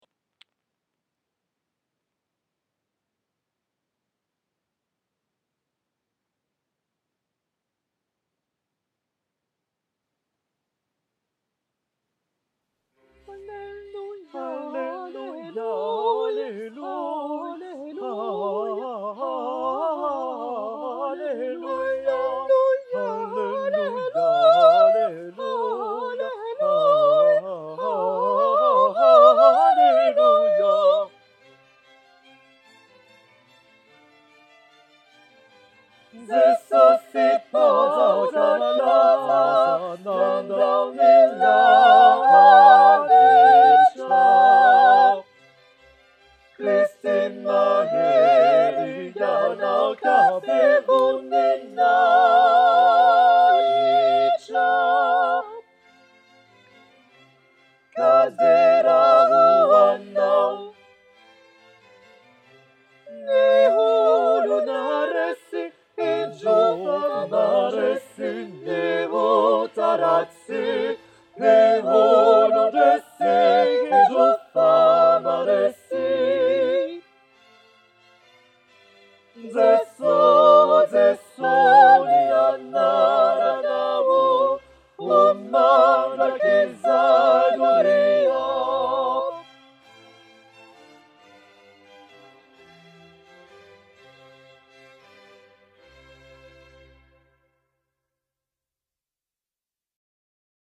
cantate_142_tutti-23_04_2014-21-42.mp3